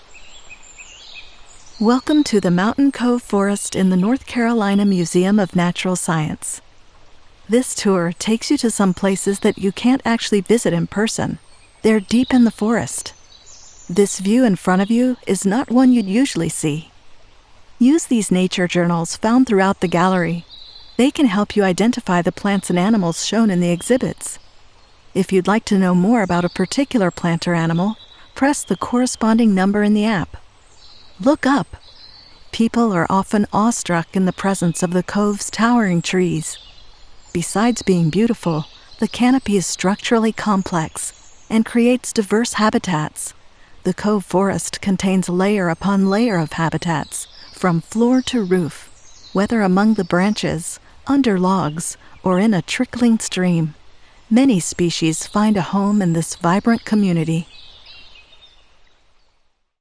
Anglais (Américain)
Commerciale, Naturelle, Fiable, Amicale, Chaude
Guide audio